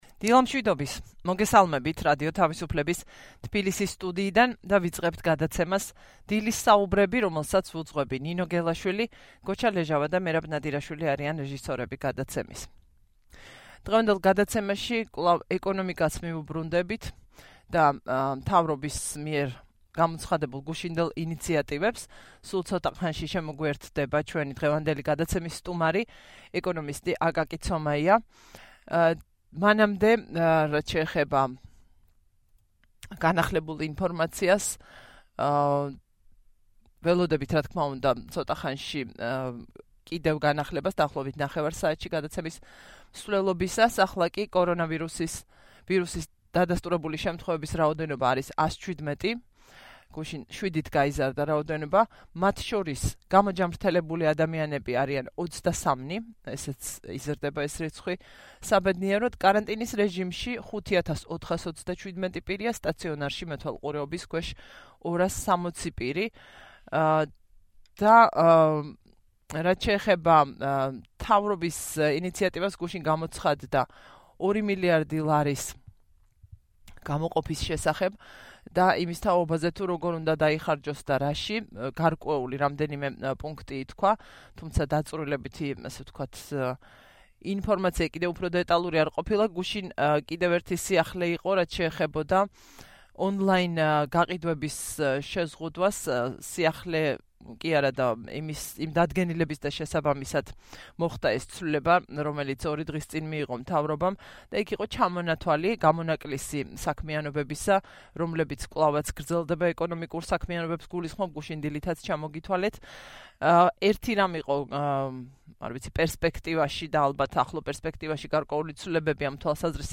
რადიო თავისუფლების ეთერში